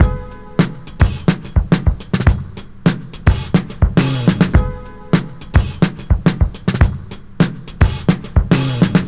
• 106 Bpm Drum Loop Sample E Key.wav
Free drum beat - kick tuned to the E note. Loudest frequency: 1682Hz
106-bpm-drum-loop-sample-e-key-twO.wav